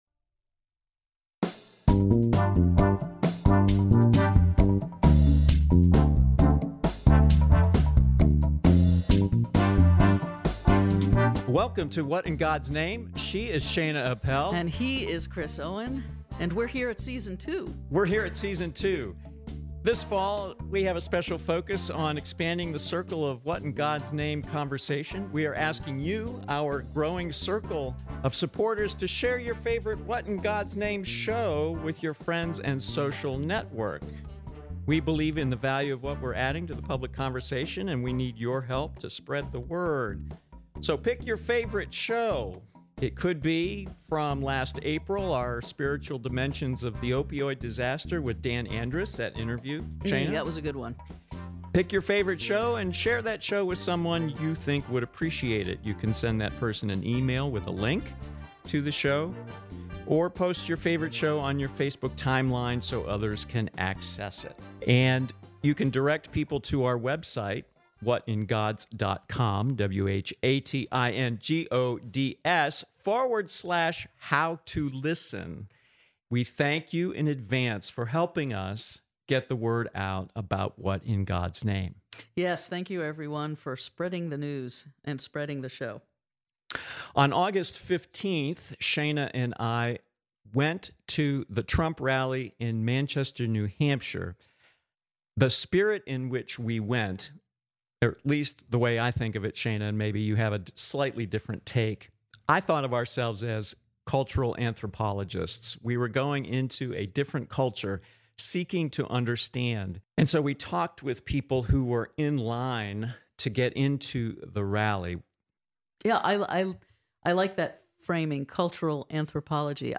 05:00 People respond to the question: What draws you to a Trump rally?